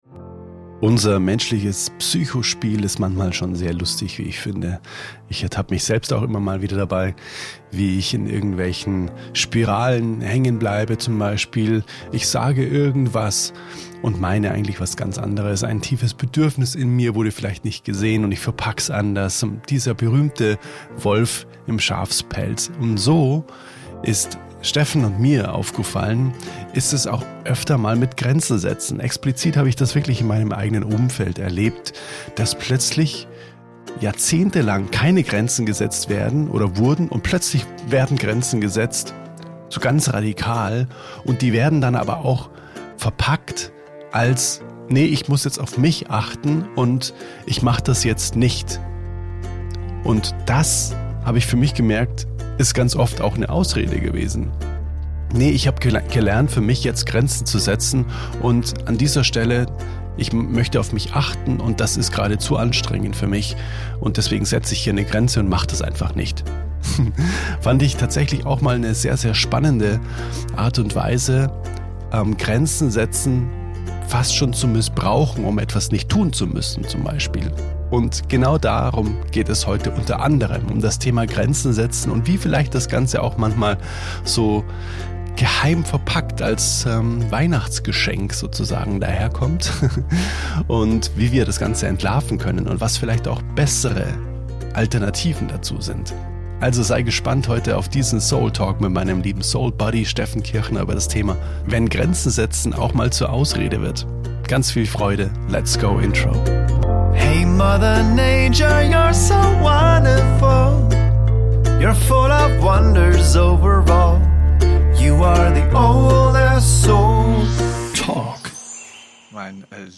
[SOULTALK] Grenzen setzen – oder doch nur bequem ausweichen? | Gespräch